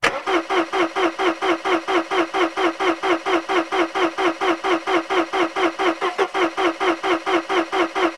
Звуки поломки автомобиля
Звук бесплодных попыток завести мотор, стартер вертит, но двигатель не схватывает